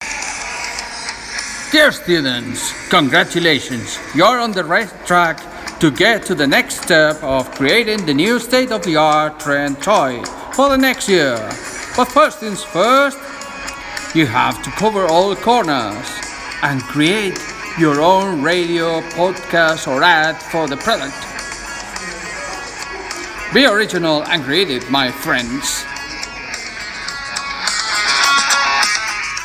radio ad gamers